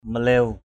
/mə-le̞ʊ/ maléw m_l@| [Bkt.]